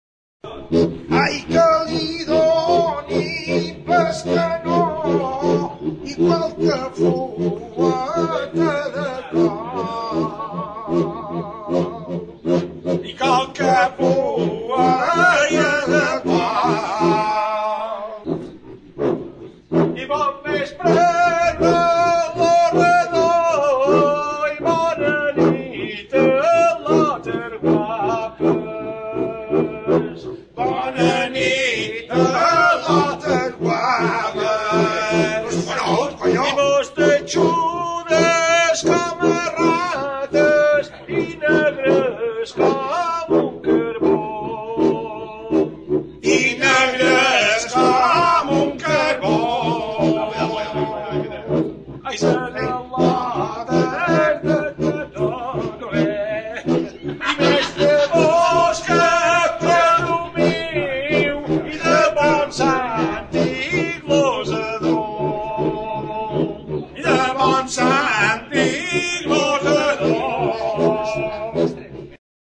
XIMBOMBA; SIMBOMBA | Soinuenea Herri Musikaren Txokoa
Enregistré avec cet instrument de musique.
Zeramikazko ontzia, larruzko mintza eta kanaberazko makila duen zanbonba da.